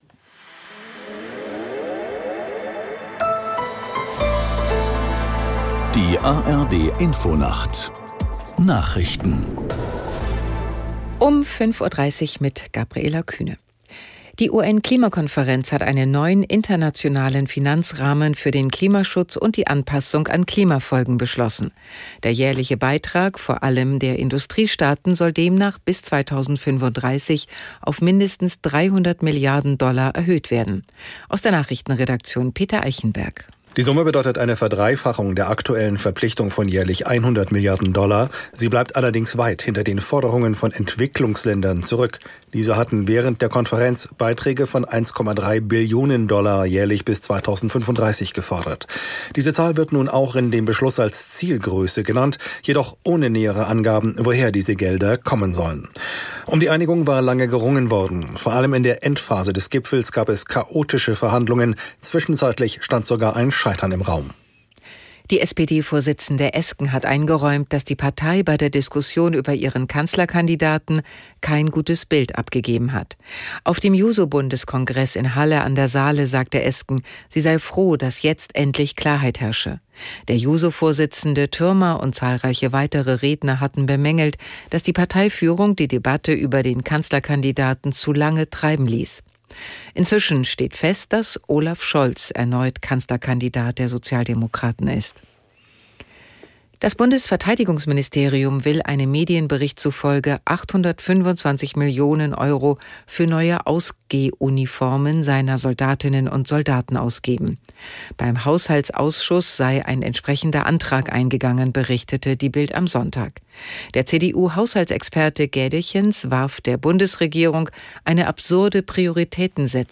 Nachrichten NDR Info Tägliche Nachrichten